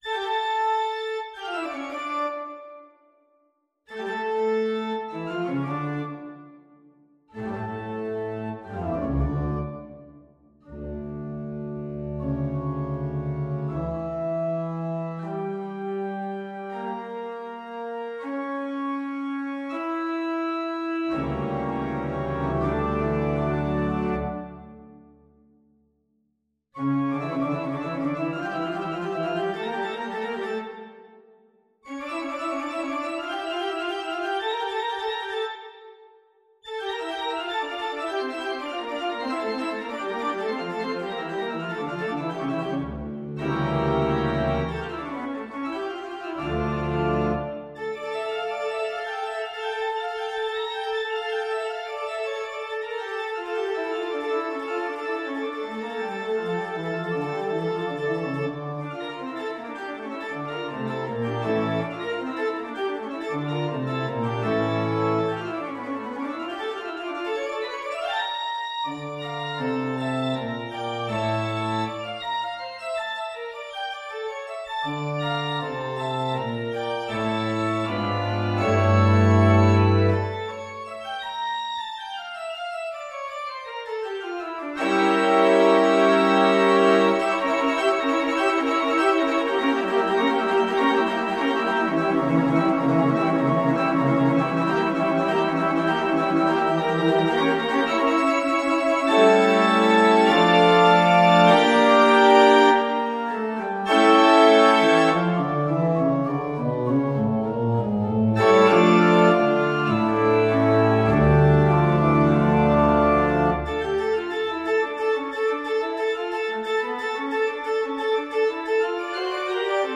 Adagio =40
4/4 (View more 4/4 Music)
Organ  (View more Advanced Organ Music)
Classical (View more Classical Organ Music)